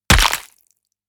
FruitHit_1.wav